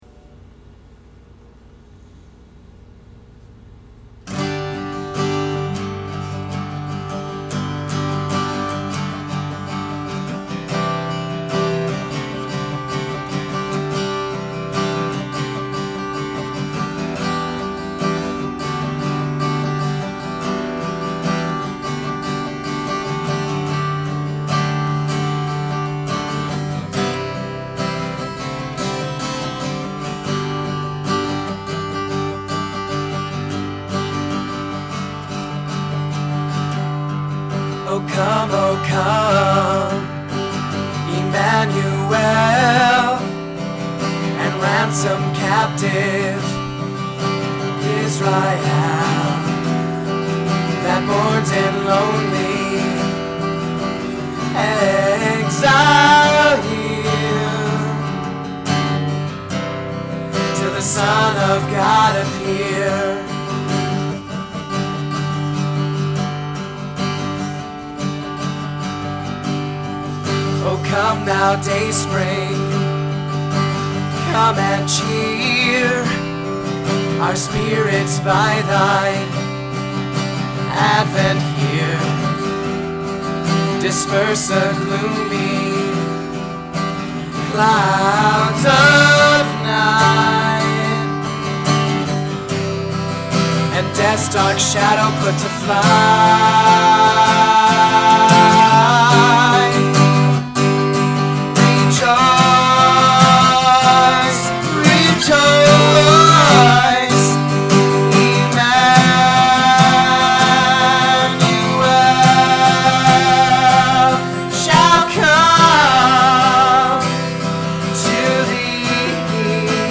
Here’s a version of “O Come, O Come, Emmanuel” that I did in church around Christmas last year and decided to record it before I forgot it. I love hearing great hymns to a new tune because then I can hear the words new.